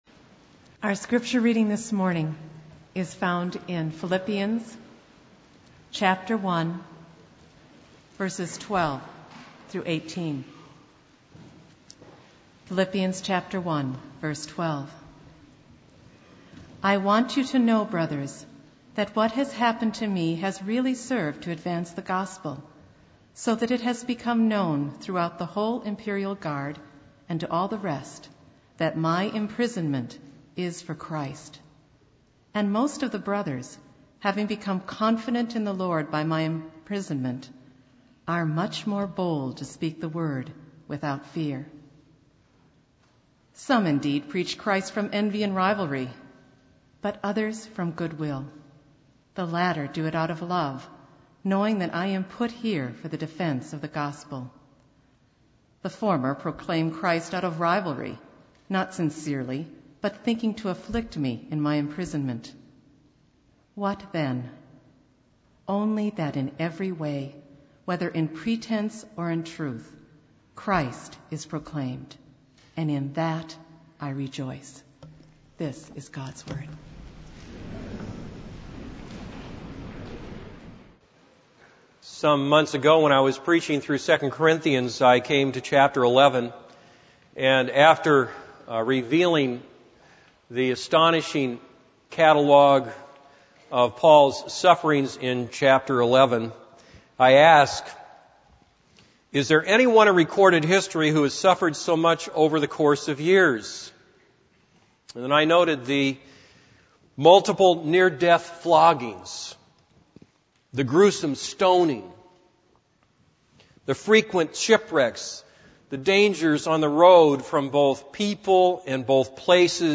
This is a sermon on Philippians 1:12-18.